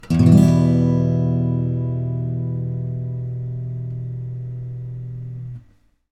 私が扱うのはアコースティックギターです。